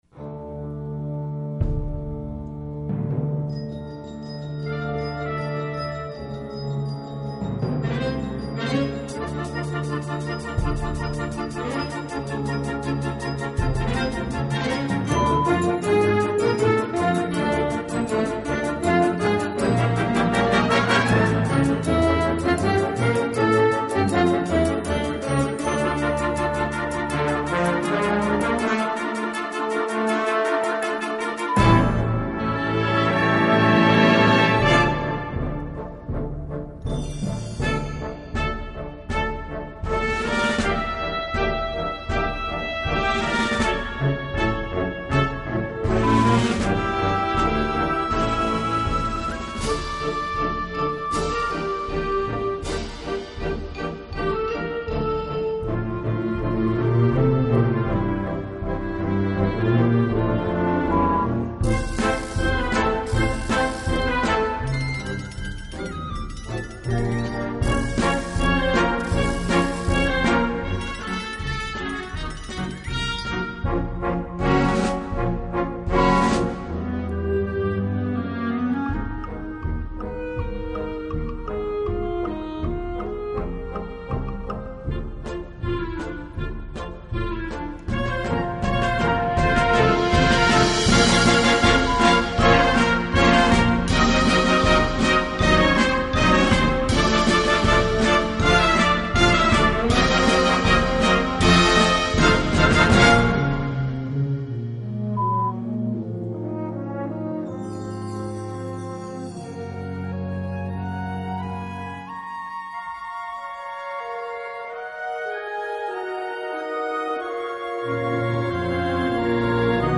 Gattung: Melodienfolge
Besetzung: Blasorchester